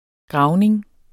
Udtale [ ˈgʁɑwneŋ ]